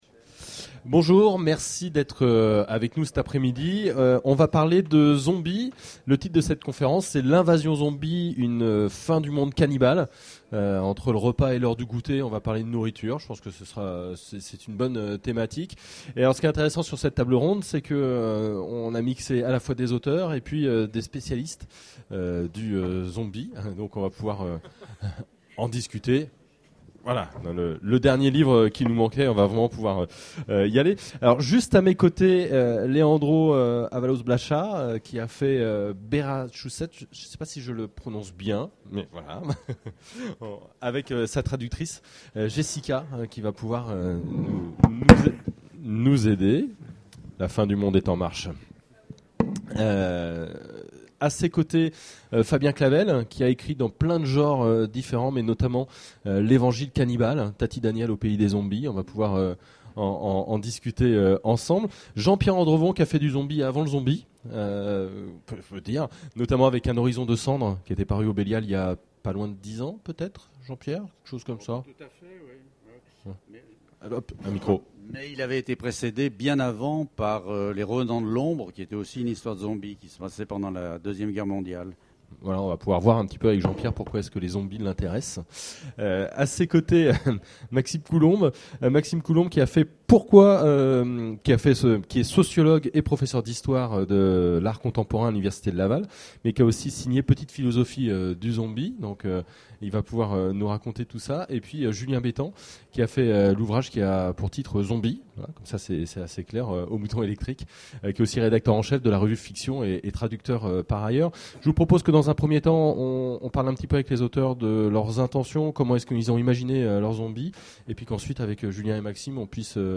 Imaginales 2014 : Conférence L'invasion zombie...